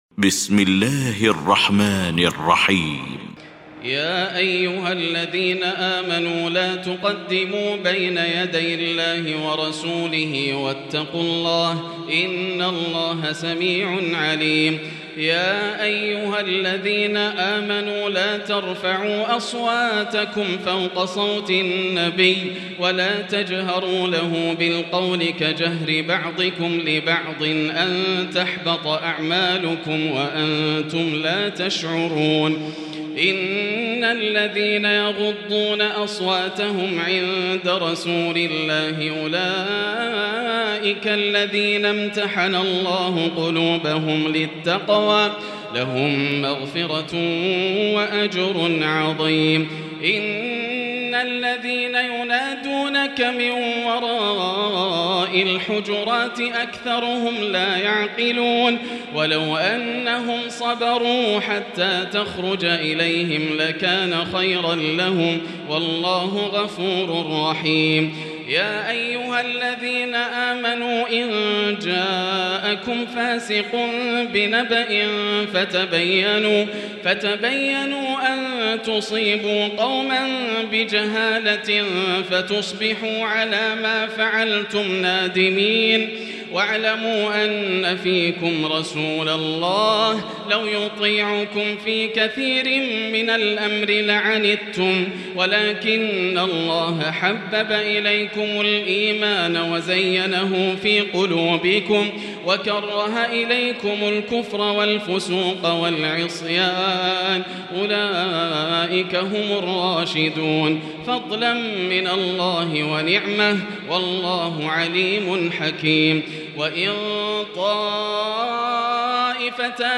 المكان: المسجد الحرام الشيخ: فضيلة الشيخ ياسر الدوسري فضيلة الشيخ ياسر الدوسري الحجرات